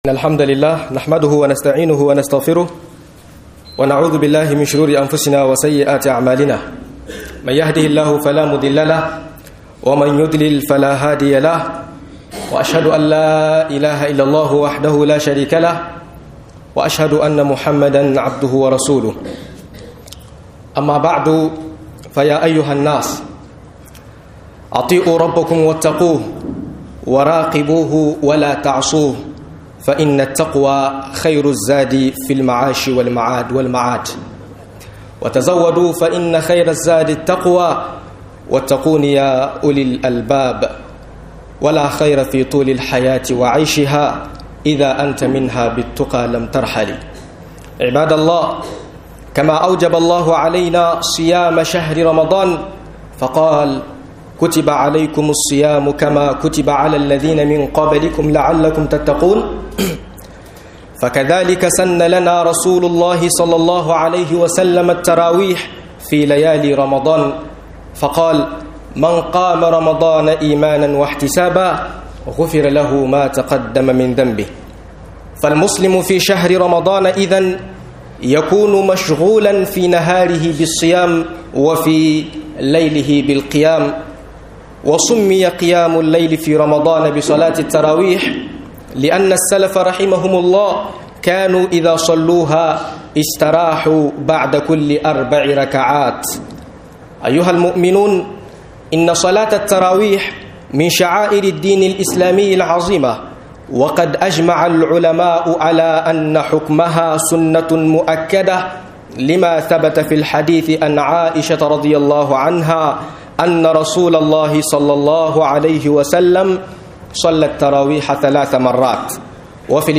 Sallar tarawihi da Kiyamullayli - MUHADARA